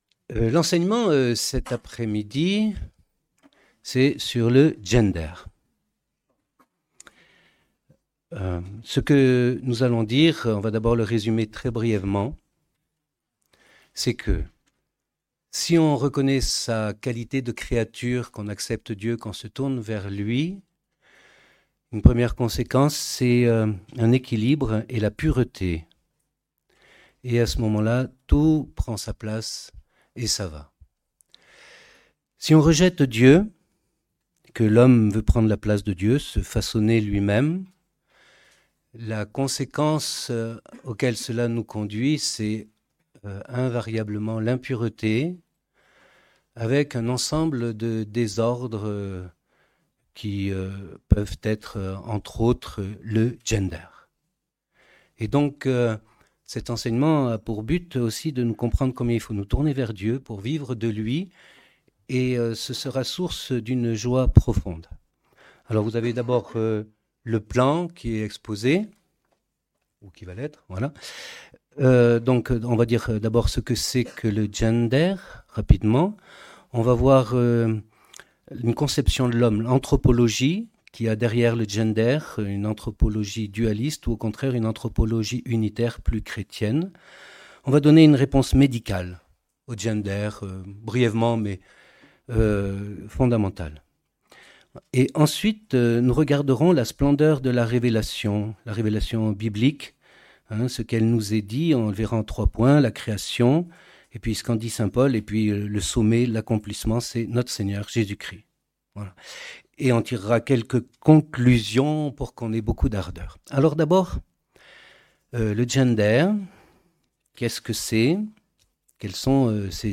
Enseignement donné à l'occasion de la Session de Toussaint 2025.